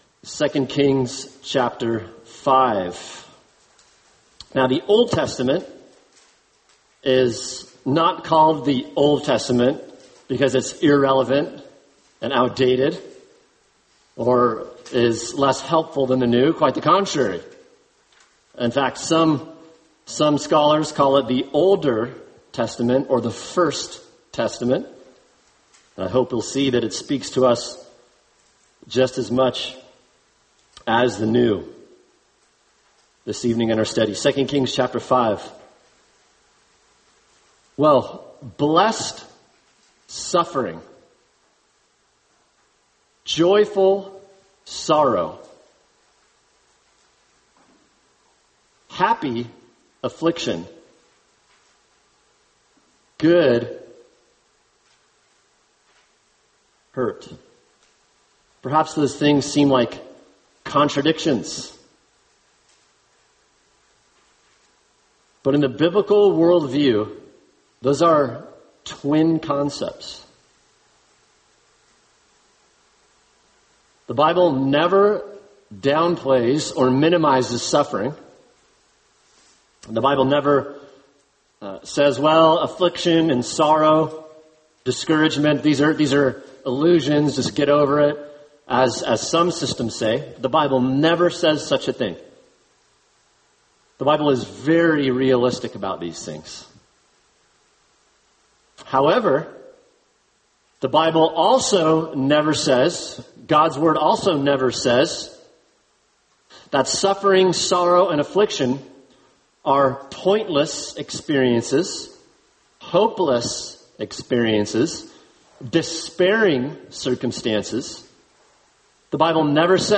[sermon] 2 Kings 5:1-19 God’s Goodness In Suffering | Cornerstone Church - Jackson Hole